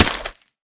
Minecraft Version Minecraft Version 1.21.5 Latest Release | Latest Snapshot 1.21.5 / assets / minecraft / sounds / block / muddy_mangrove_roots / break6.ogg Compare With Compare With Latest Release | Latest Snapshot